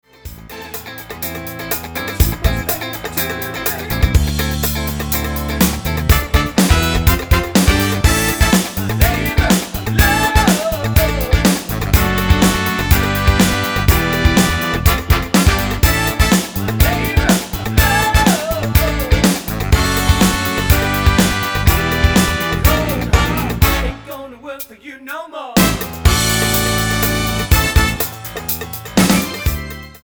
Tonart:Am mit Chor
Die besten Playbacks Instrumentals und Karaoke Versionen .